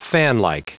Prononciation audio / Fichier audio de FANLIKE en anglais
Prononciation du mot : fanlike